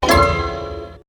UI_SFX_Pack_61_21.wav